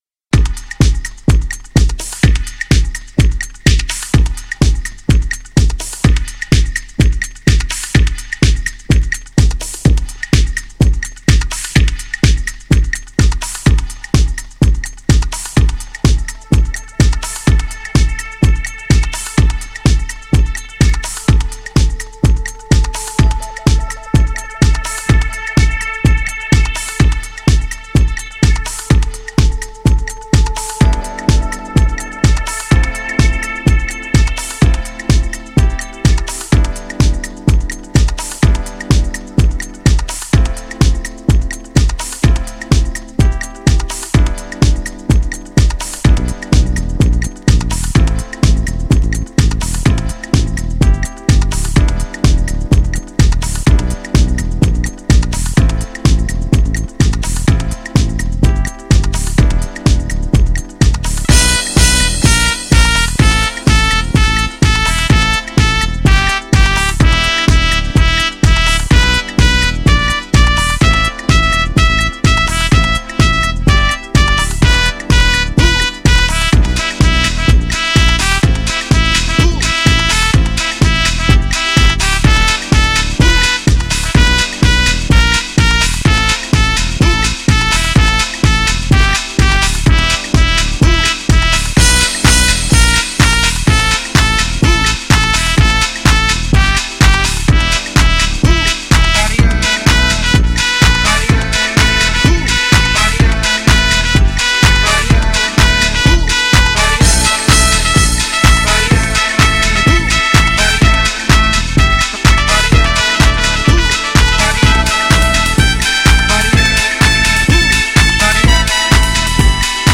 GENRE House
BPM 126〜130BPM